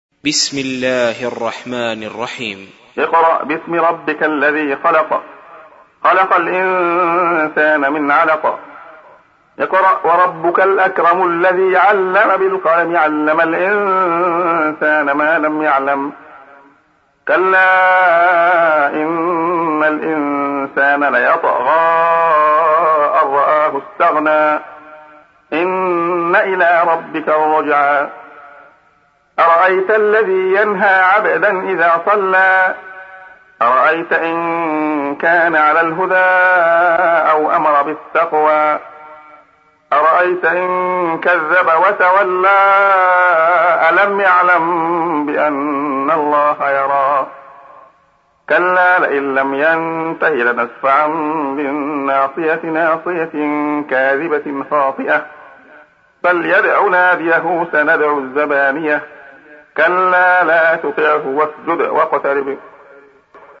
سُورَةُ العَلَقِ بصوت الشيخ عبدالله الخياط